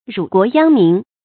辱國殃民 注音： ㄖㄨˇ ㄍㄨㄛˊ ㄧㄤ ㄇㄧㄣˊ 讀音讀法： 意思解釋： 使國家受辱，人民遭殃。